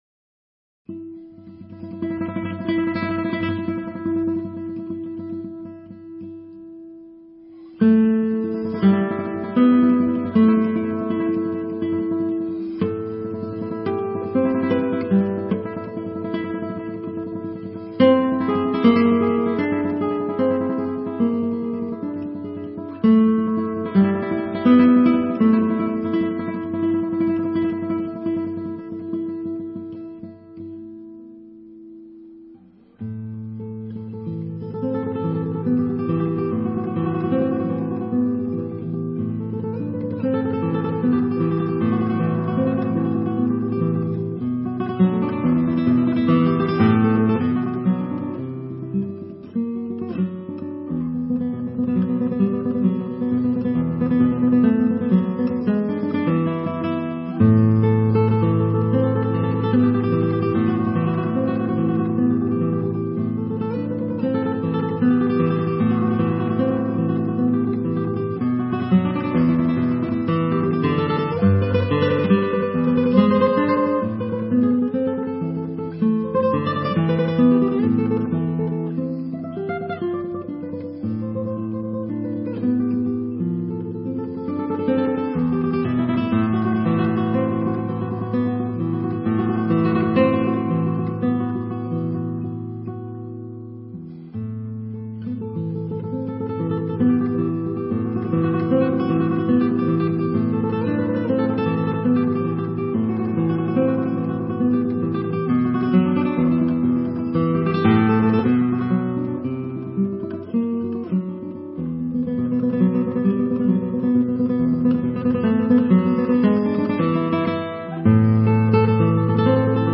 Melodifoliz, Concours national de guitare a Ceyzeriat, Festival Biennale en Revermont, Art et musique